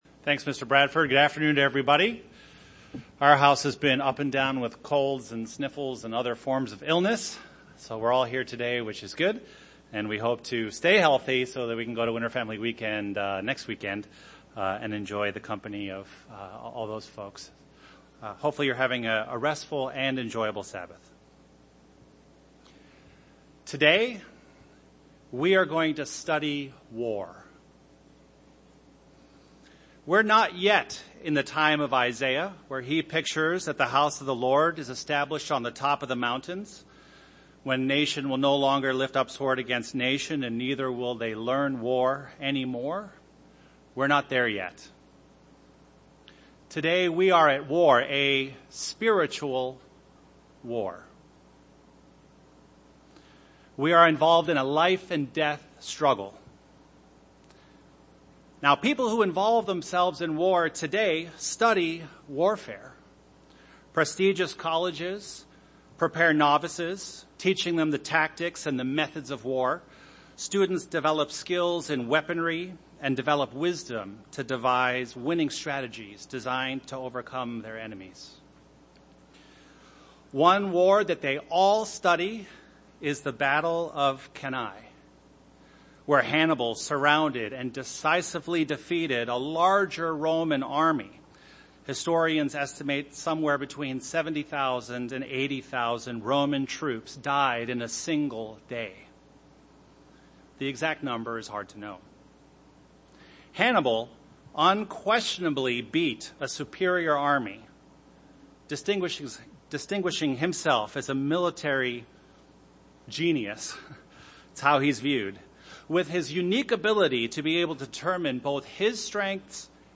This sermon takes a look at how we as Christians must continue to stand against Satan and the evils of the world, and how God gives us the strength to do so.
Given in Chicago, IL